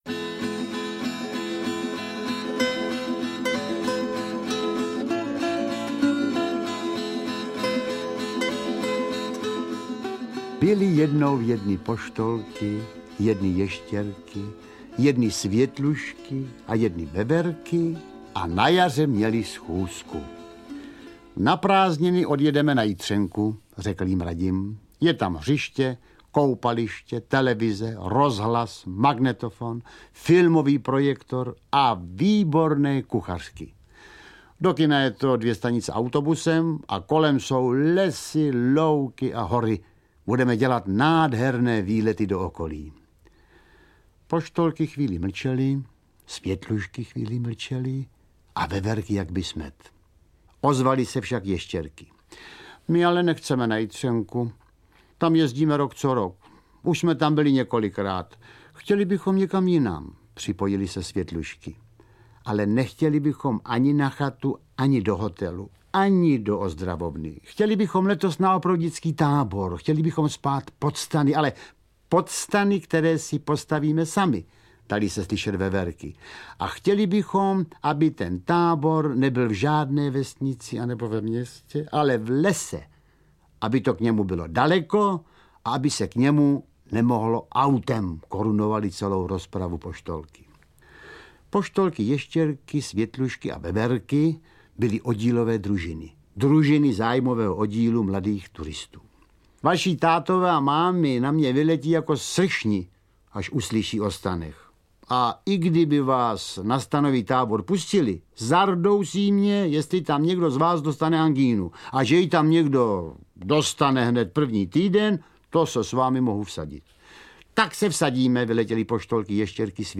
Interpreti:  Jiřina Bohdalová, Iva Janžurová, Jiří Sovák